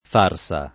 farsa
[ f # r S a ]